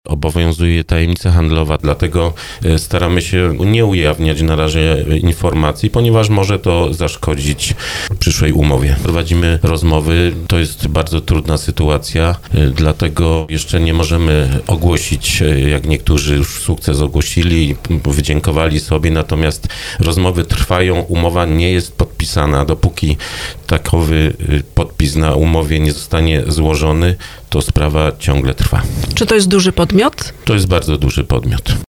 Będziemy robić wszystko, aby porodówka była nadal w dąbrowskim szpitalu – mówił podczas wywiadu w RDN Małopolska starosta Lesław Wieczorek, ale nie zdradził szczegółów dotyczących negocjacji.